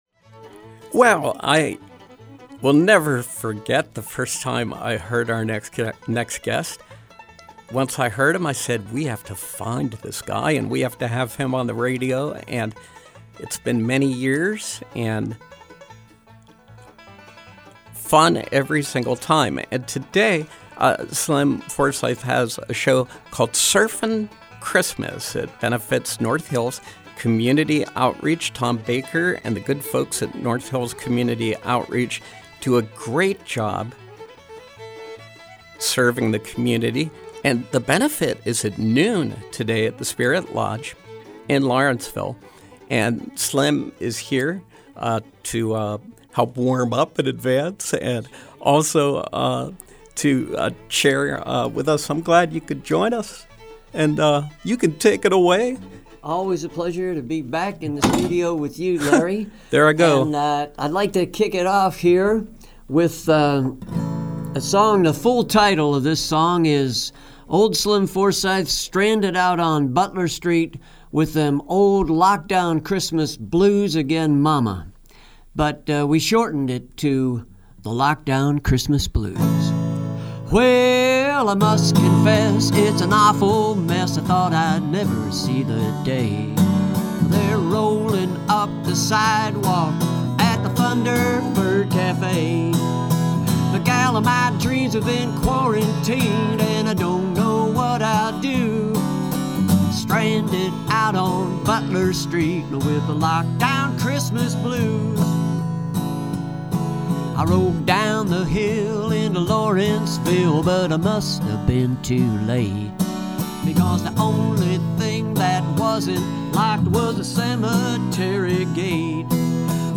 In-Studio Pop Up
Interviews